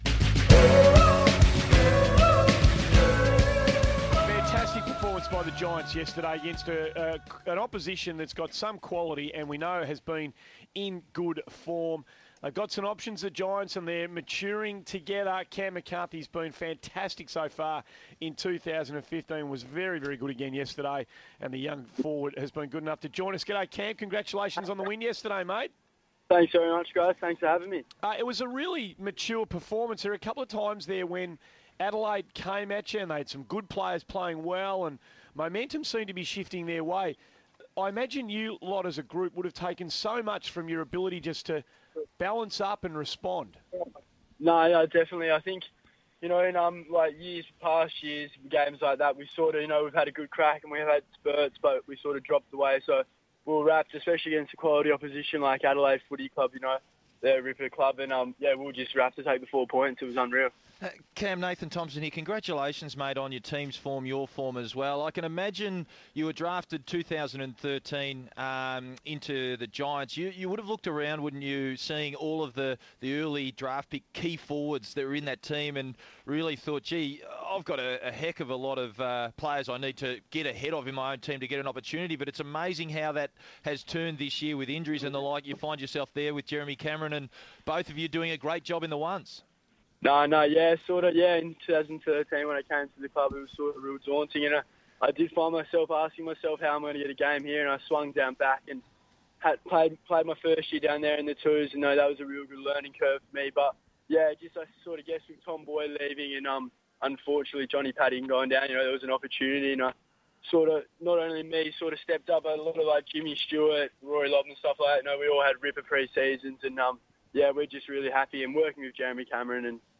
GWS forward Cam McCarthy chats with Andy Maher, Scott Lucas, Nathan Thompson and Rohan Connolly following the Giants' 24-point win over Adelaide.